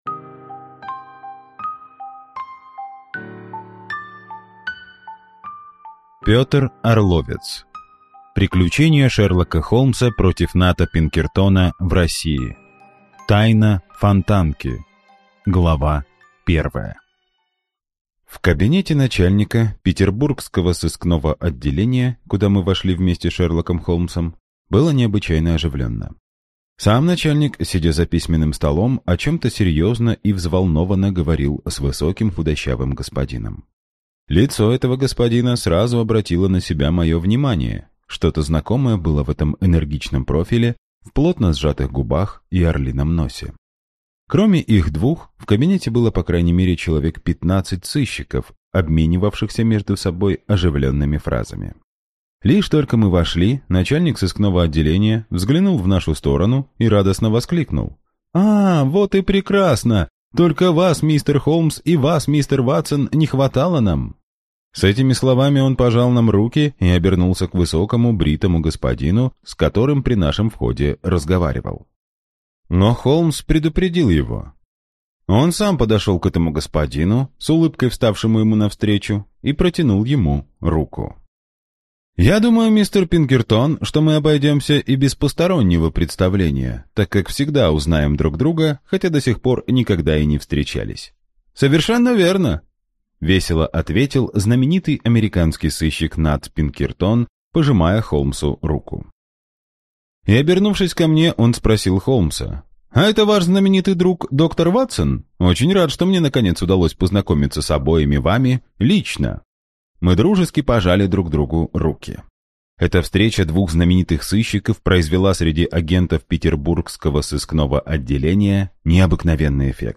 Аудиокнига Приключения Шерлока Холмса против Ната Пинкертона в России | Библиотека аудиокниг